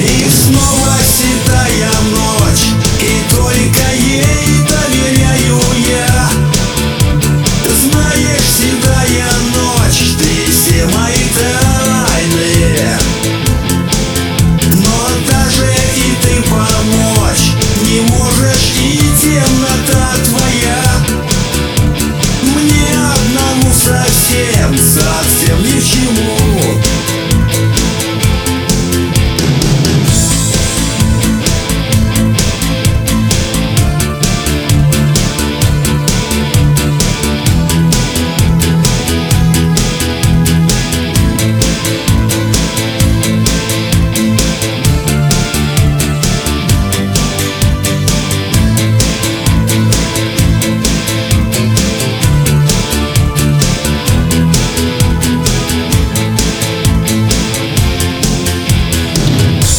• Качество: 320, Stereo
русский рок
Нейросеть
AI cover